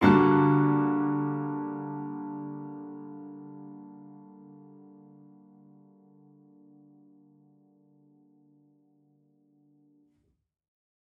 Index of /musicradar/gangster-sting-samples/Chord Hits/Piano
GS_PiChrd-Dsus4min6.wav